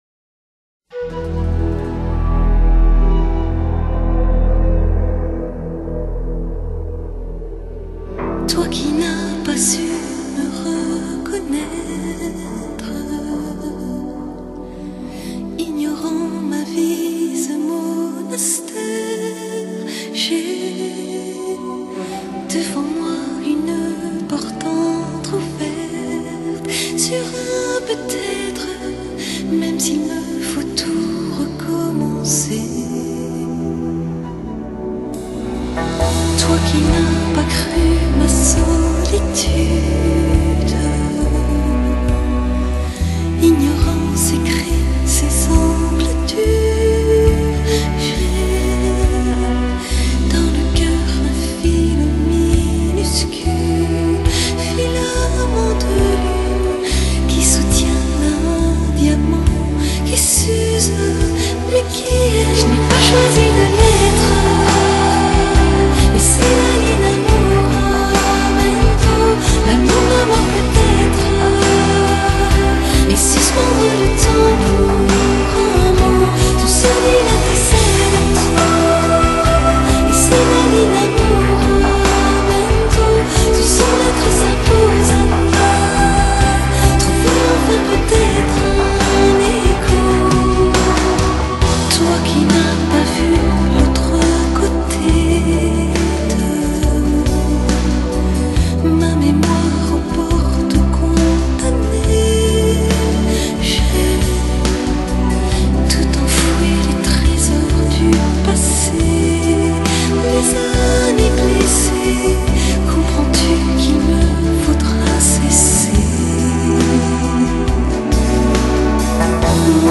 Genre: Pop, Pop-Rock, Electronica
她的歌声另类、却富亲和力，穿透力极强，宛如天籁。